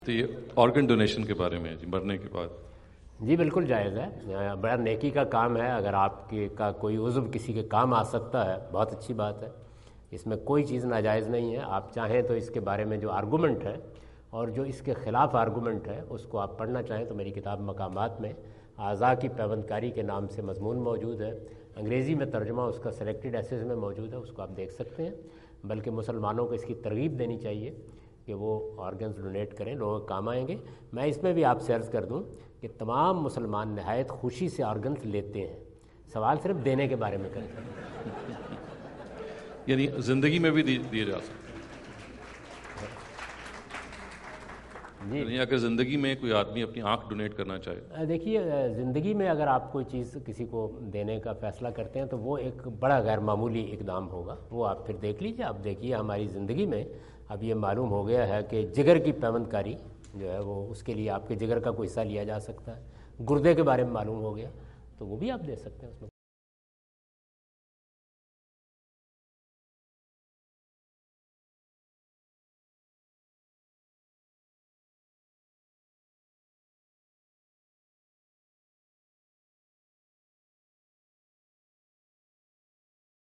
Category: English Subtitled / Questions_Answers /
Javed Ahmad Ghamidi answer the question about "Organ Donation Before Death" asked at Aapna Event Hall, Orlando, Florida on October 14, 2017.